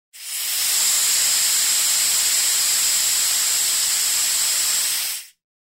На этой странице собраны разнообразные звуки змей: от устрашающего шипения до угрожающих вибраций хвоста.
Звук шипения змеи